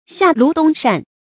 發音讀音
成語簡拼 xlds 成語注音 ㄒㄧㄚˋ ㄌㄨˊ ㄉㄨㄙ ㄕㄢˋ 成語拼音 xià lú dōng shàn 發音讀音 常用程度 常用成語 感情色彩 貶義成語 成語用法 聯合式；作賓語、定語；含貶義 成語結構 聯合式成語 產生年代 古代成語 近義詞 不合時宜 反義詞 雪中送炭 英語翻譯 stoves in summer and fans in winter